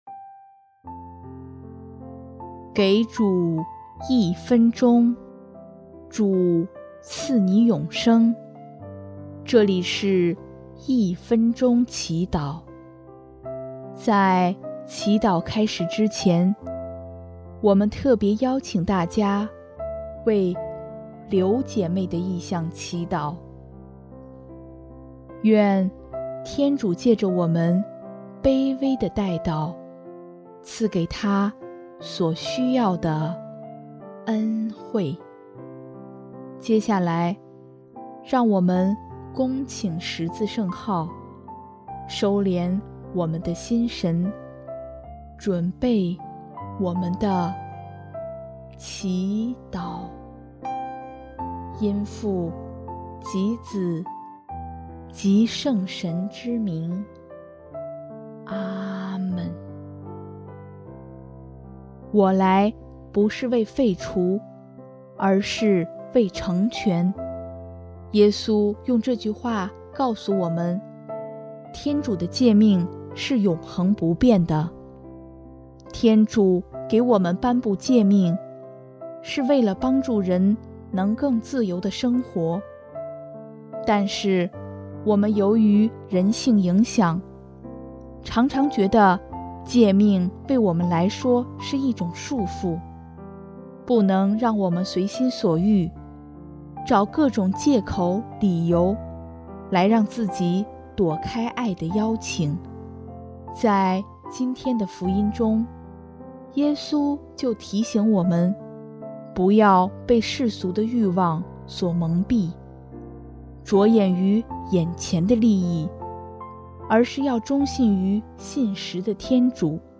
主日赞歌